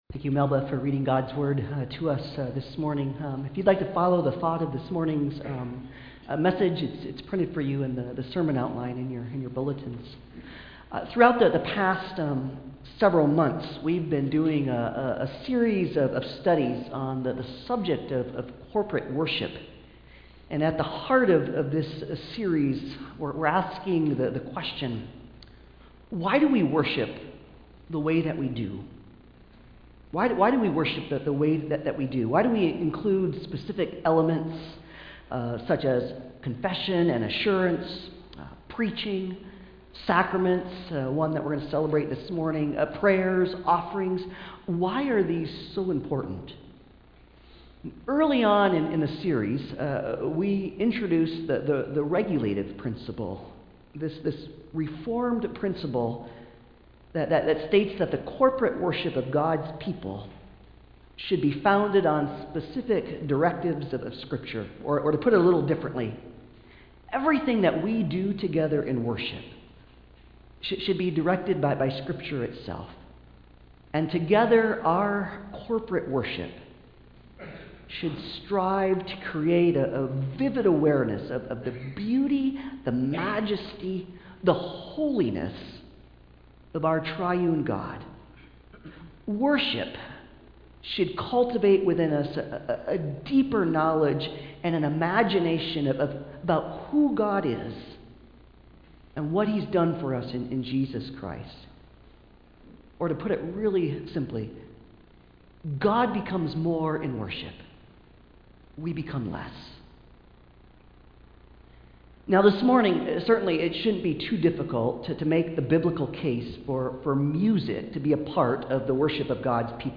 Colossians 3:12-17 Service Type: Sunday Service « Confession & Assurance Professing our Faith